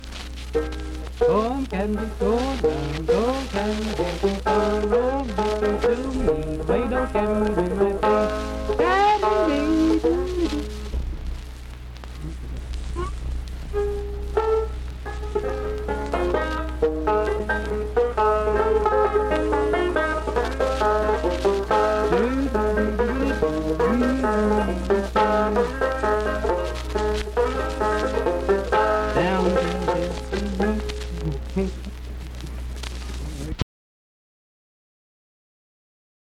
Vocal performance accompanied by banjo.
Voice (sung), Banjo